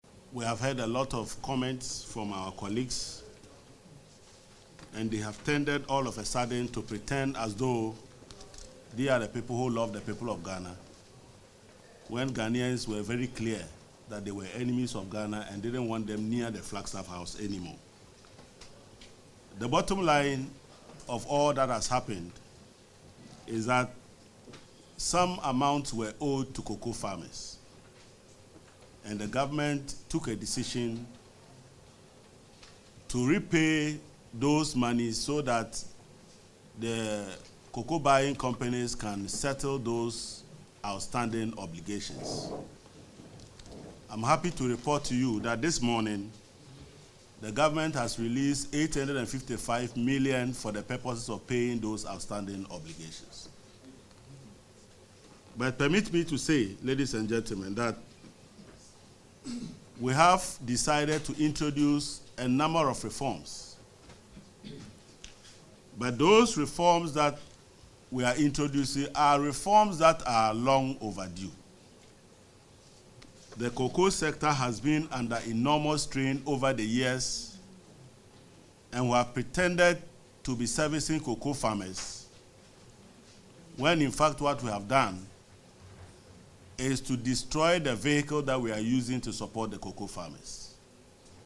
Isaac Adongo, the chairman of the finance committee, justified the restrictions in an interview with journalists in Ghana’s parliament, calling them tough but essential measures to save a sector that has been severely strained financially for years.